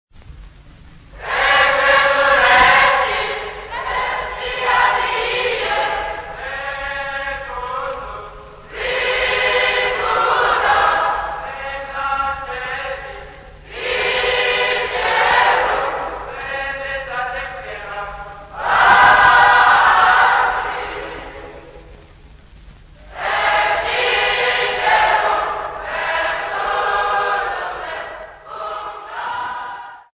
Popular music--Africa
Dance music
Field recordings
The choir of young men and boys, of mixed tribes, mostly Wemba and unaccompanied